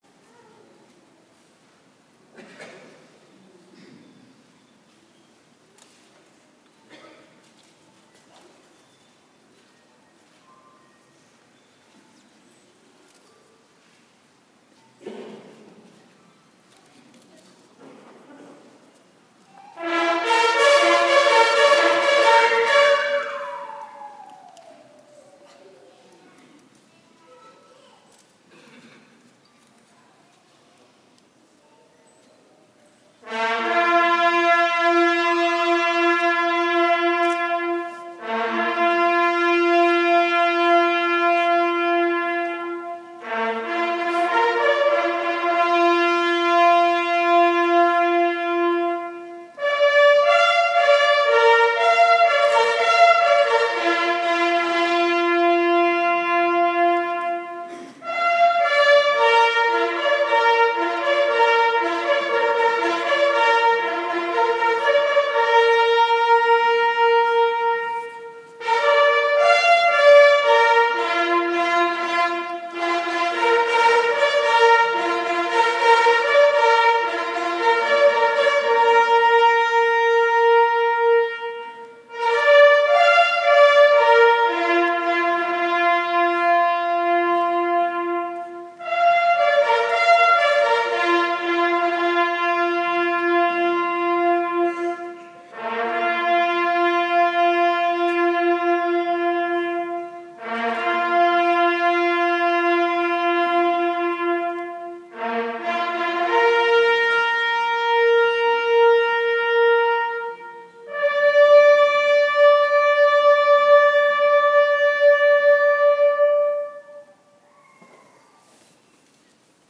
Bugler does Last Post Ceremony at Menin Gate